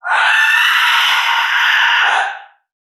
NPC_Creatures_Vocalisations_Puppet#39 (search_04).wav